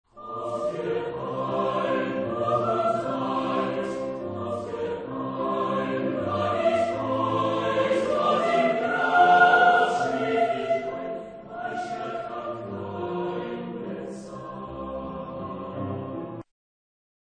Genre-Style-Forme : Chanson ; Folklore ; Profane
Caractère de la pièce : andante
Type de choeur : SATB  (4 voix mixtes )
Instruments : Piano (1)
Tonalité : do mode de sol